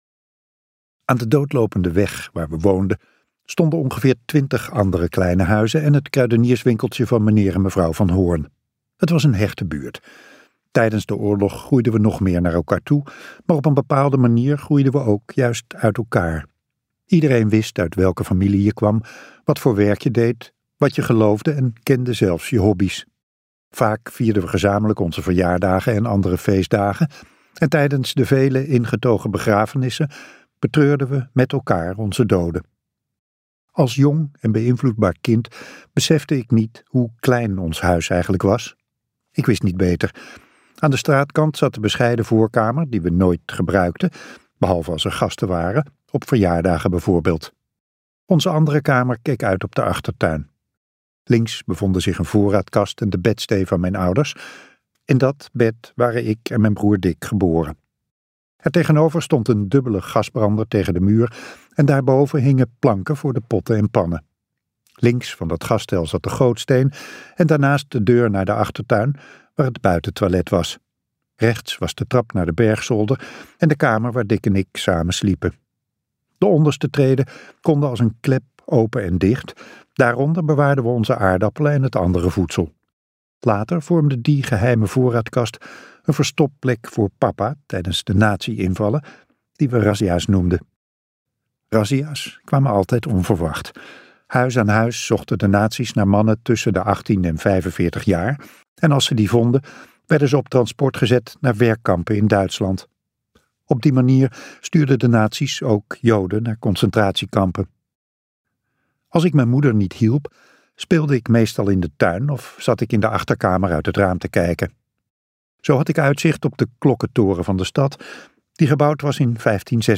KokBoekencentrum | De oorlog die mijn ouders niet hoorden luisterboek